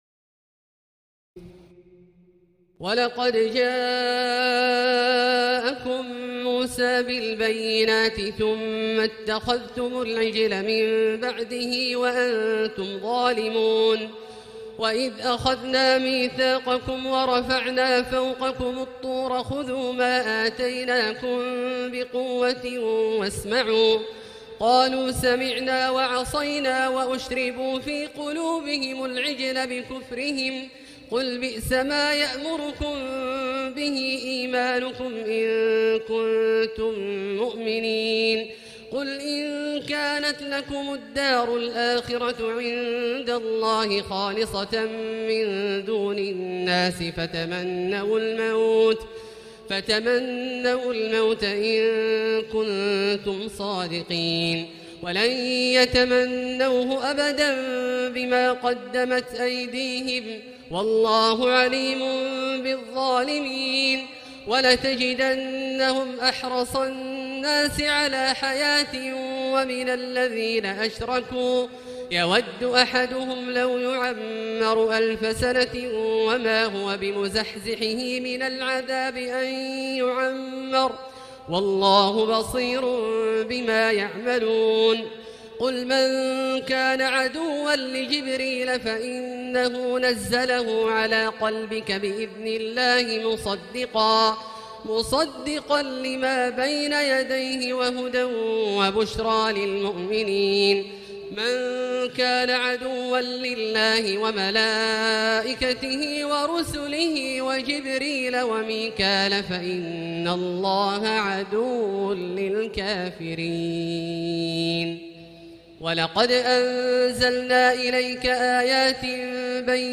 تراويح الليلة الأولى رمضان 1439هـ من سورة البقرة (92-157) Taraweeh 1st night Ramadan 1439H from Surah Al-Baqara > تراويح الحرم المكي عام 1439 🕋 > التراويح - تلاوات الحرمين